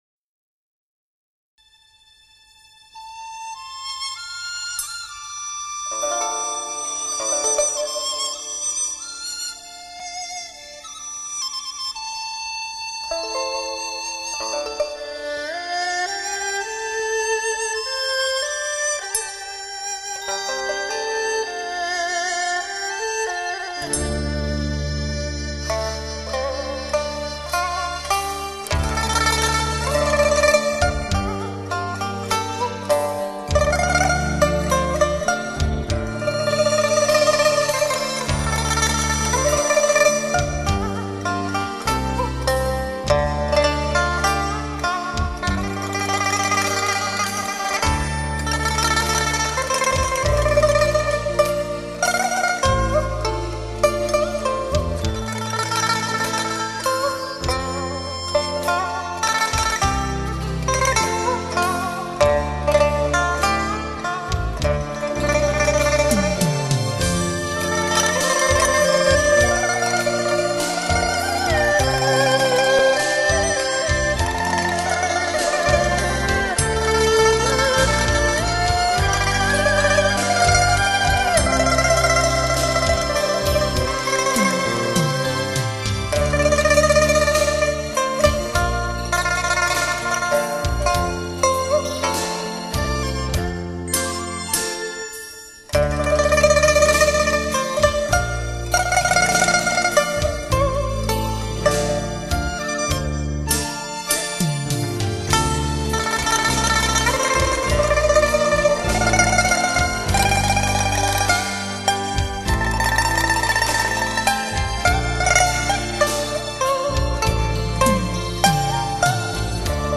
这是一张用琵琶、古筝、二胡、笛子、中阮、柳琴